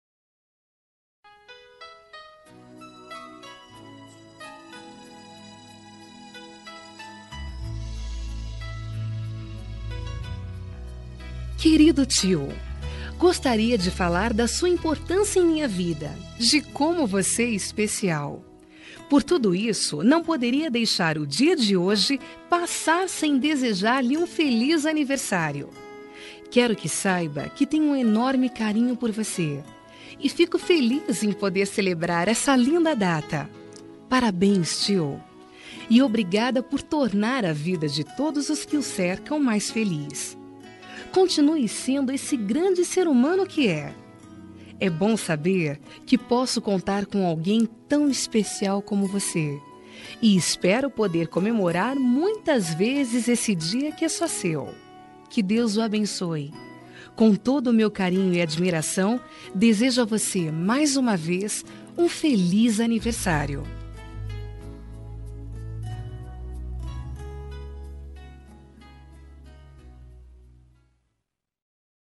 Aniversário de Tio – Voz Feminina – Cód: 929